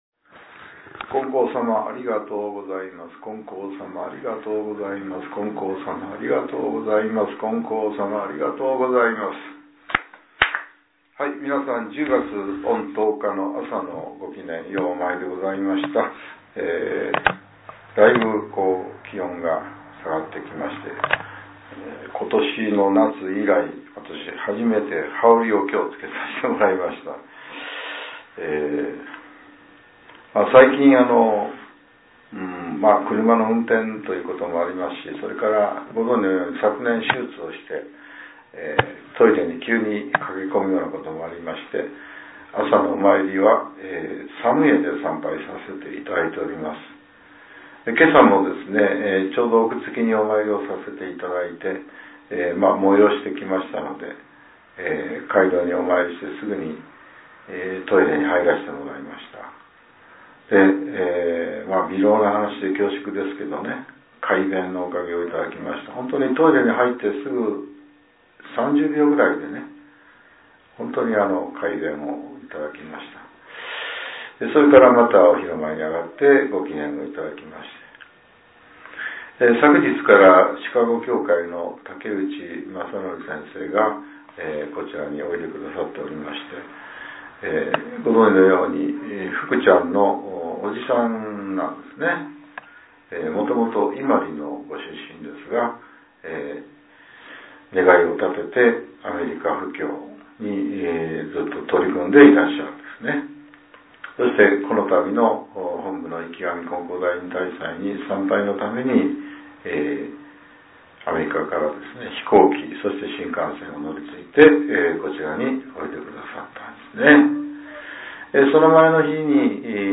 令和８年２月１８日（朝）のお話が、音声ブログとして更新させれています。 きょうは、前教会長による「言いっぱなしではない」です。